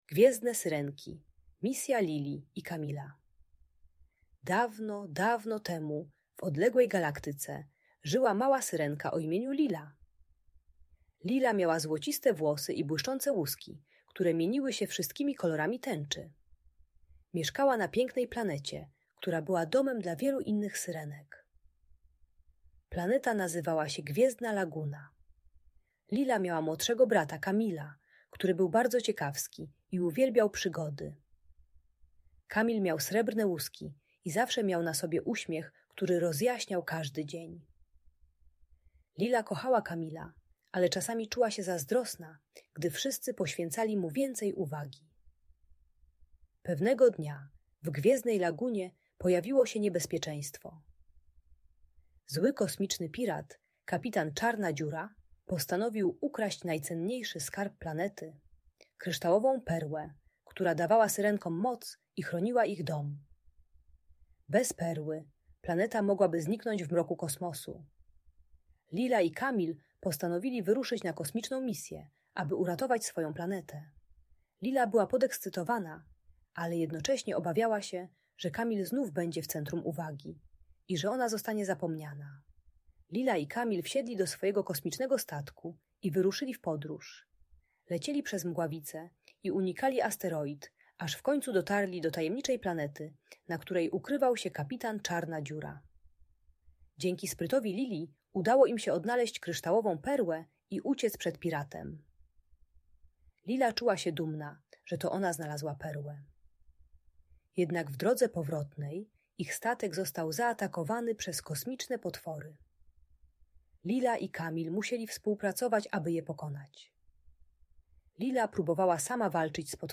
Gwiezdne Syrenki: Historia Lili i Kamila - Audiobajka